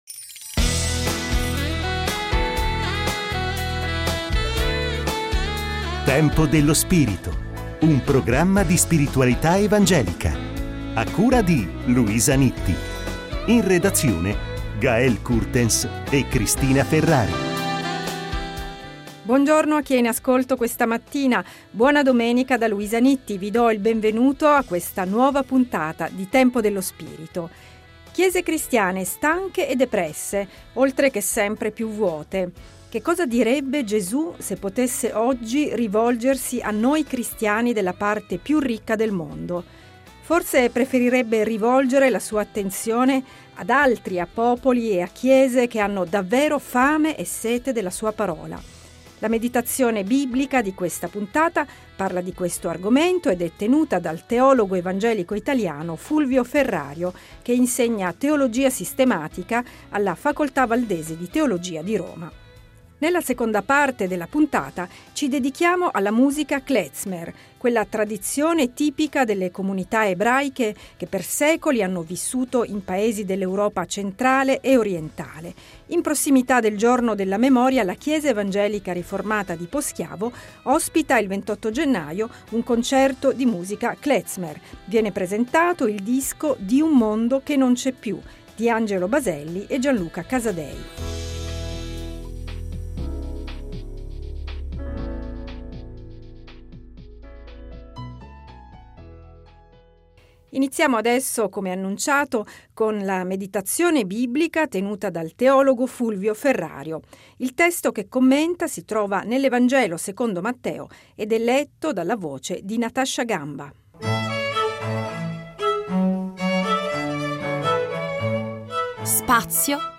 La meditazione biblica